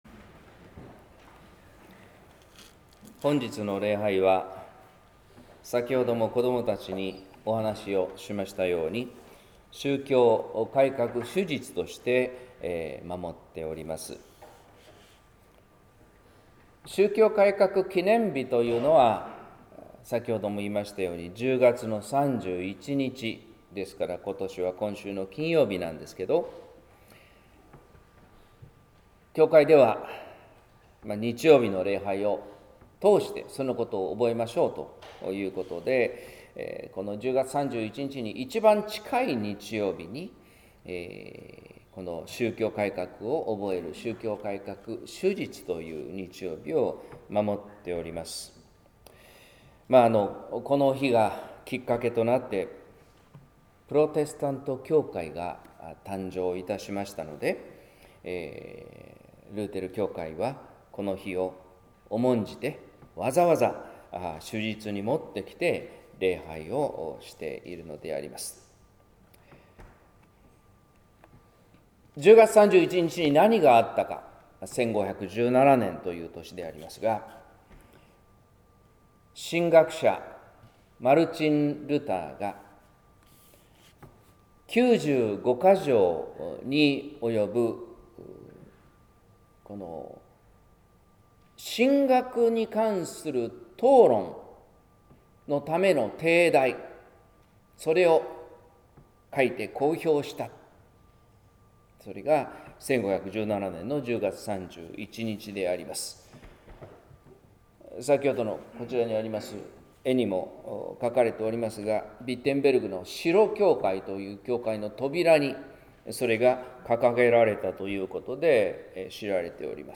説教「自由という正しさ」（音声版）